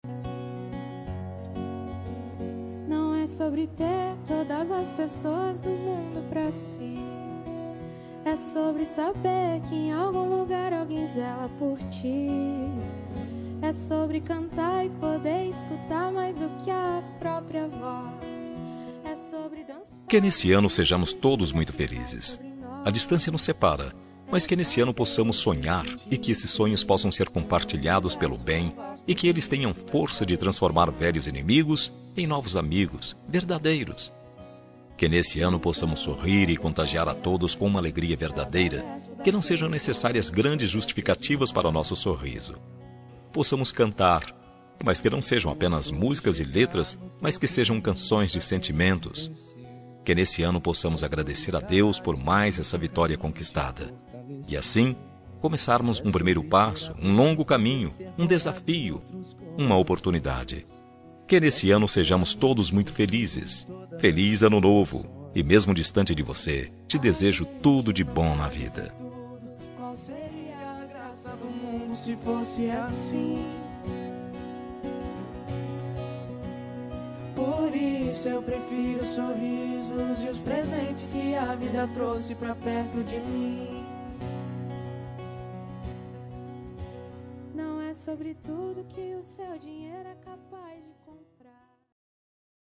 Ano Novo – Distante – Voz Masculina – Cód: 6432